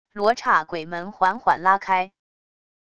罗刹鬼门缓缓拉开wav音频